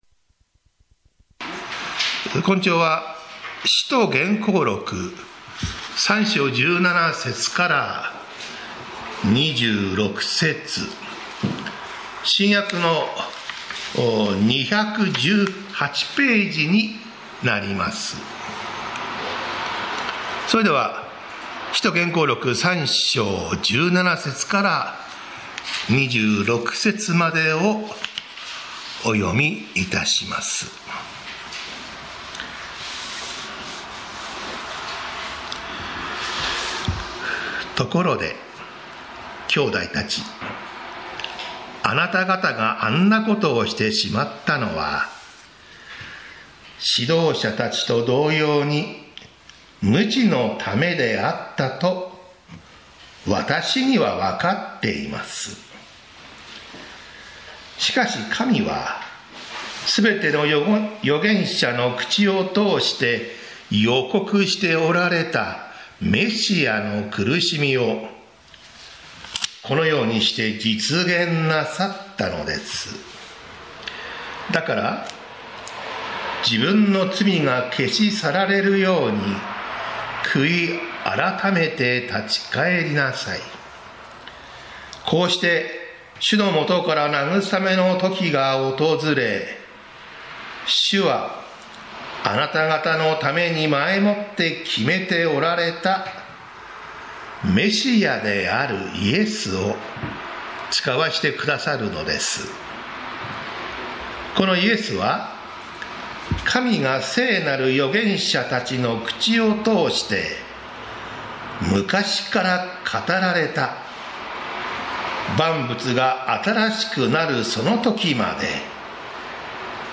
祝福へと導く神の御心 宇都宮教会 礼拝説教